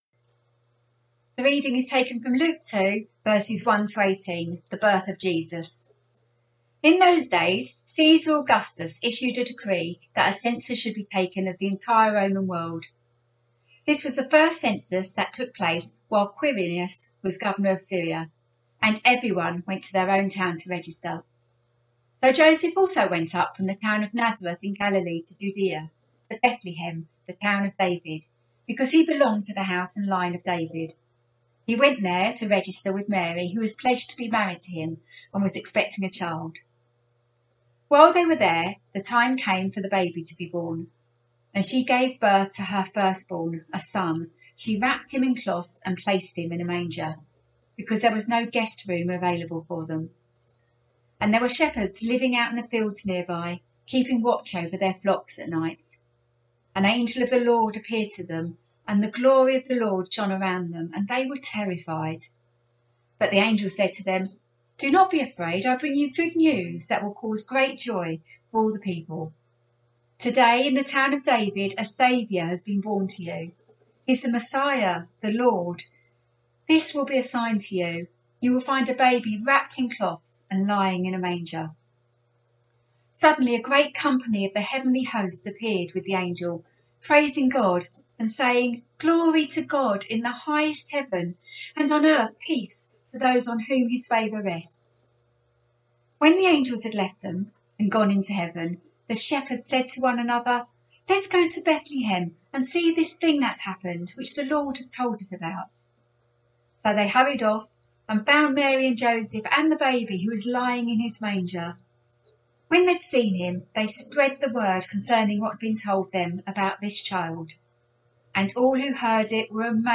A message from the series "Christmas 2020."